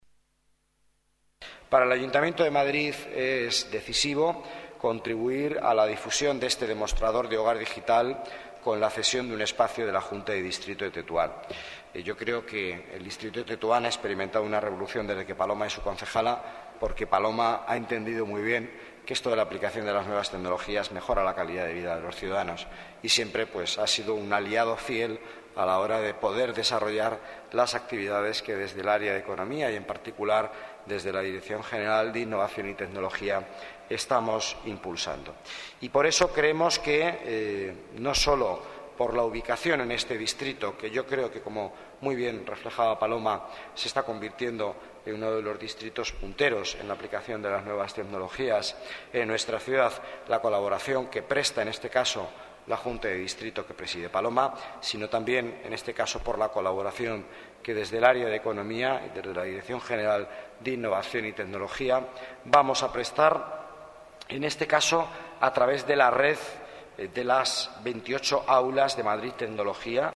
Miguel Ángel Villanueva destaca en la presentación del proyecto en Tetuán que Madrid se convierte en la capital del I+D+i aplicada al hogar
Nueva ventana:Miguel Ángel Villanueva, delegado de Economía y Empleo